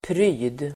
Uttal: [pry:d]